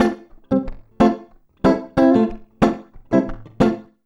92FUNKY  2.wav